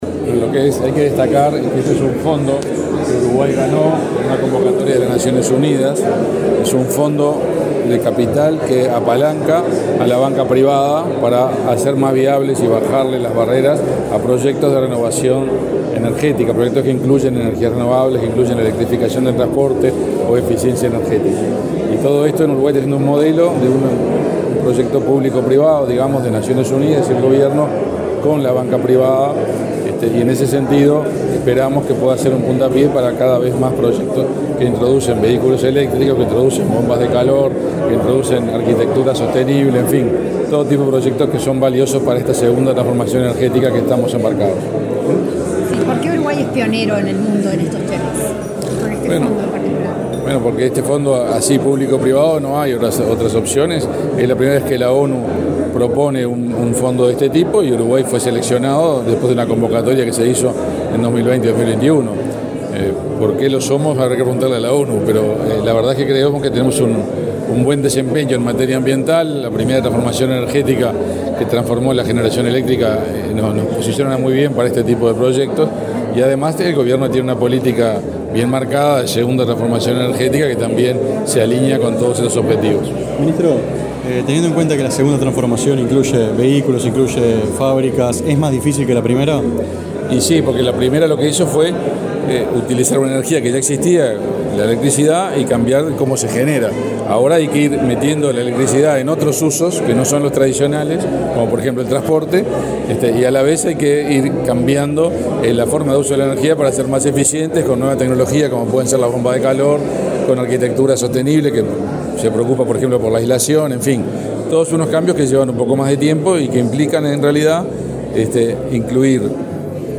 Declaraciones del ministro de Industria, Omar Paganini
El Ministerio de Industria, Energía y Minería (MIEM) y el Sistema de las Naciones Unidas en Uruguay realizaron una conferencia de prensa en la que presentaron los primeros proyectos aprobados en el marco del Fondo de Innovación en Energías Renovables (REIF, por sus siglas en inglés). Luego del acto, el ministro Omar Paganini, dialogó con la prensa.